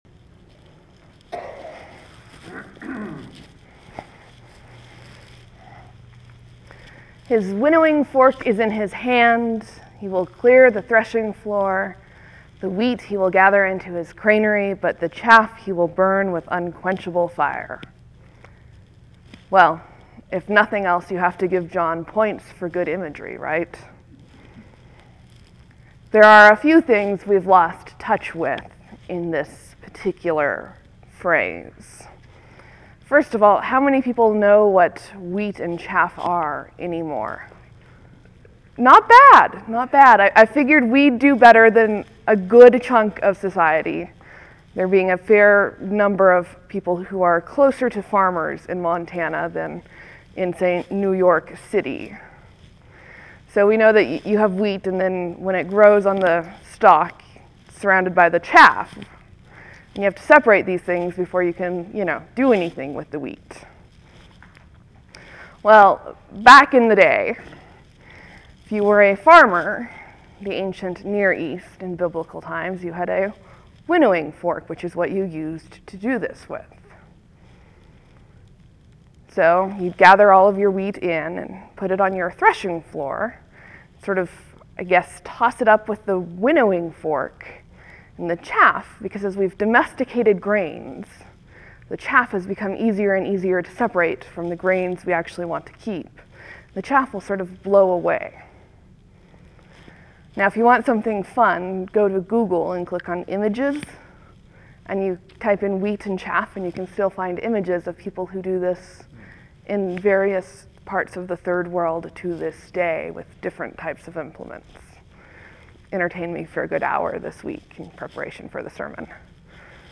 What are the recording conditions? (There may be a few seconds pause before the sermon starts.)